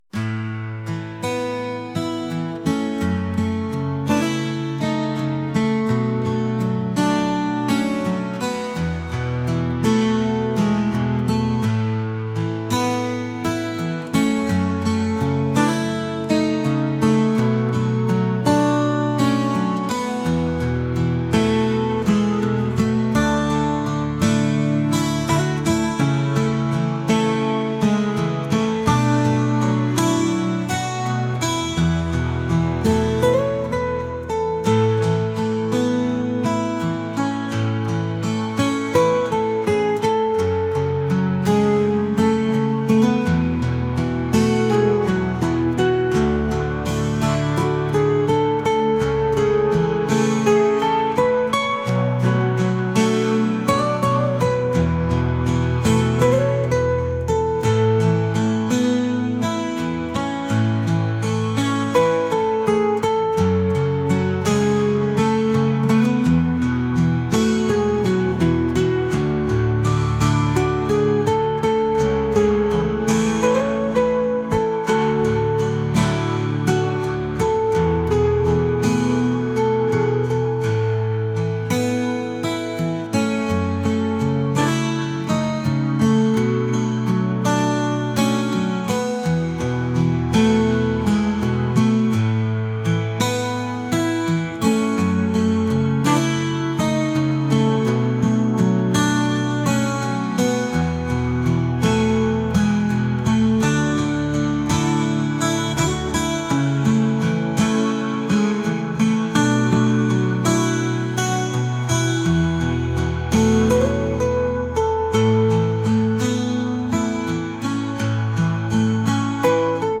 acoustic | pop | folk